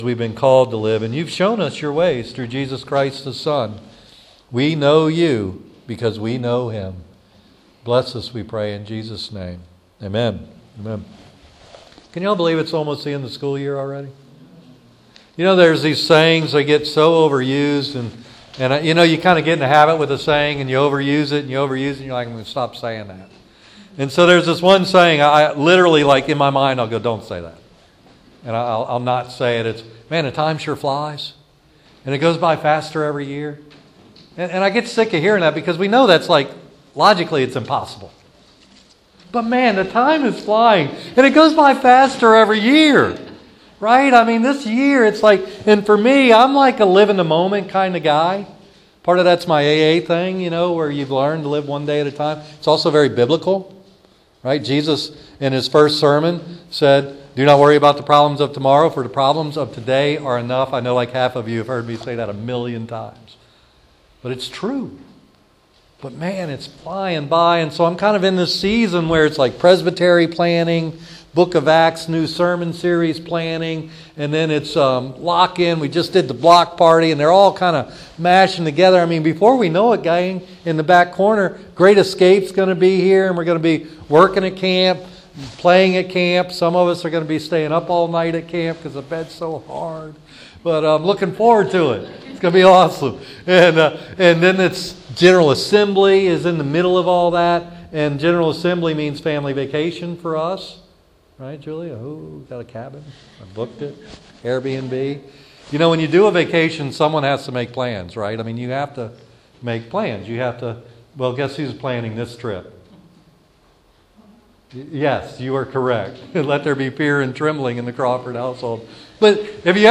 Acts Sermon Series